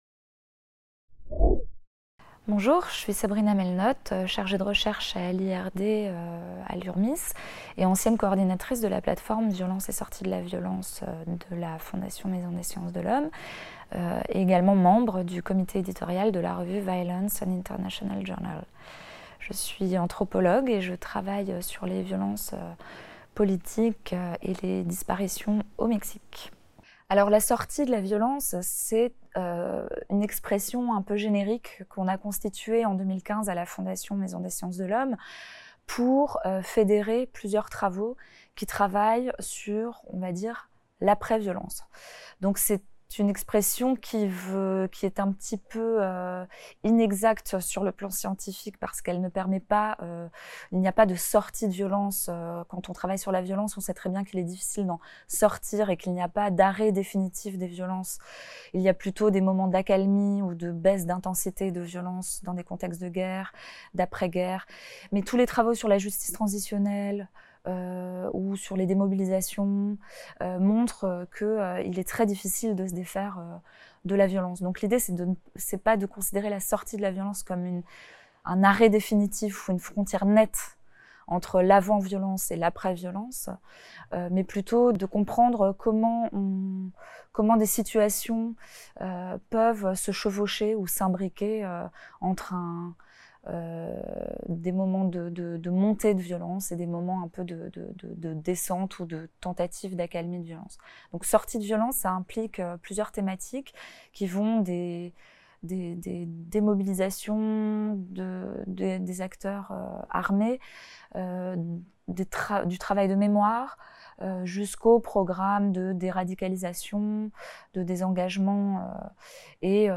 Sortie du premier numéro de la revue Violence - Interview